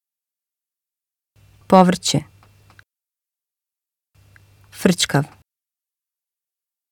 Je krijgt twee woorden te horen.